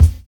DISCO 8 BD.wav